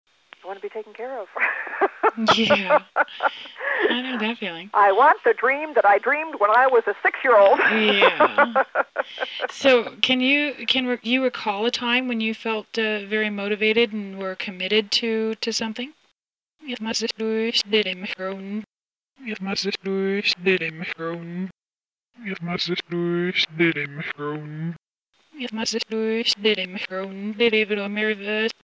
Tags: Reverse Speech Analysis Reverse Speech samples Reverse Speech clips Reverse Speech sounds Reverse Speech